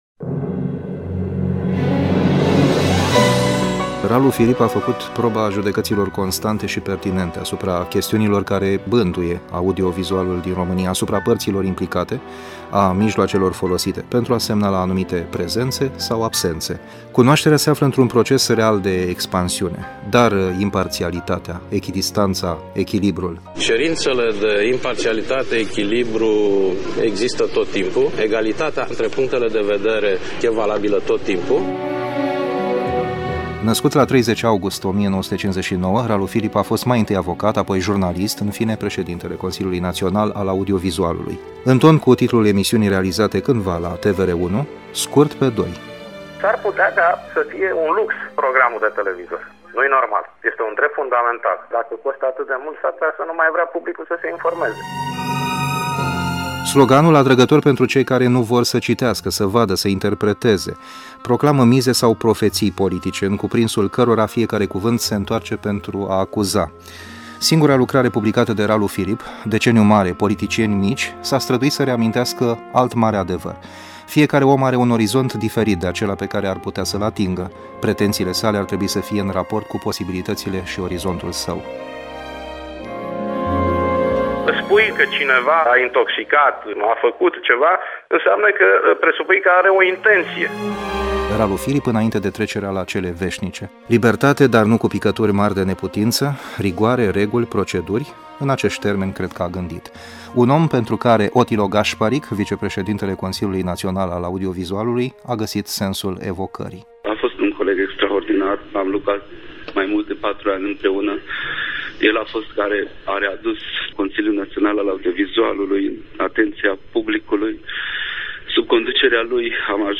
Audio Feature